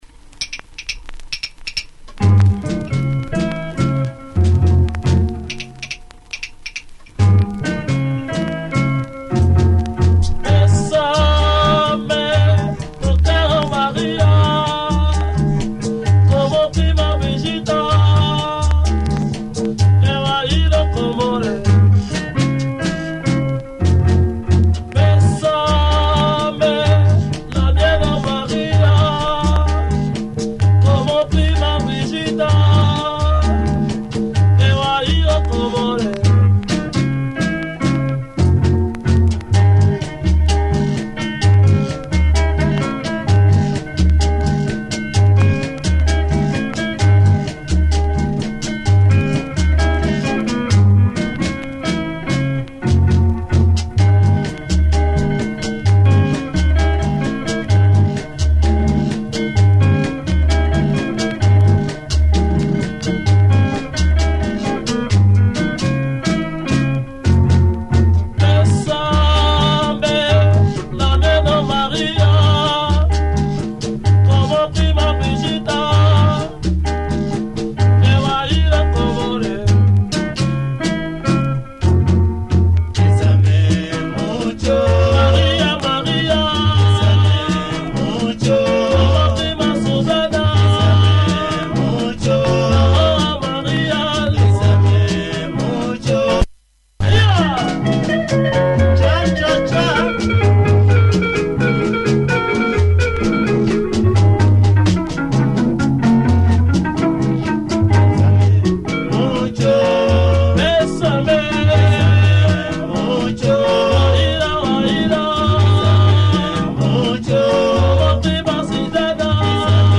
Sounds like a Congolese group, recorded in Mombasa
slowburn dark cha-cha rumba number